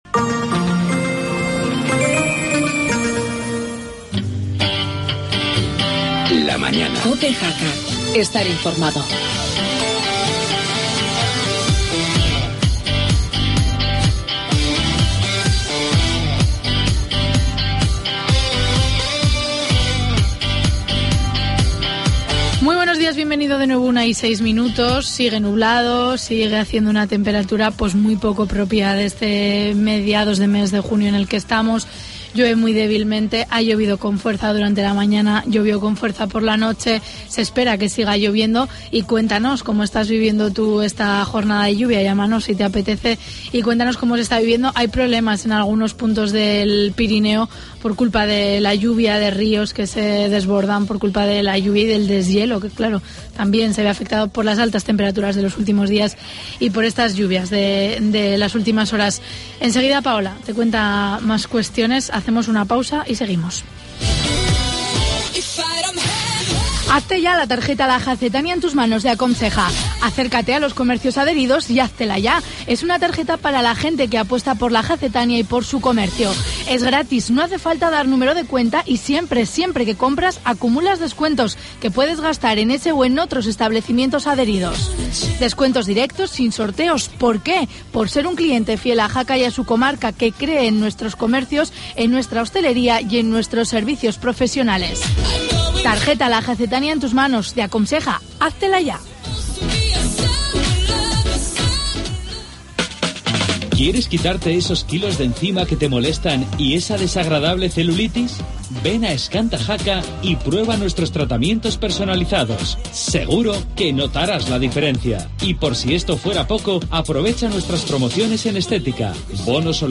Redacción digital Madrid - Publicado el 18 jun 2013, 17:06 - Actualizado 14 mar 2023, 12:56 1 min lectura Descargar Facebook Twitter Whatsapp Telegram Enviar por email Copiar enlace En la segunda parte de La Mañana hablamos con el alcalde de Jaca, Víctor Barrio, sobre la integración del Hospital de Jaca en el Salud.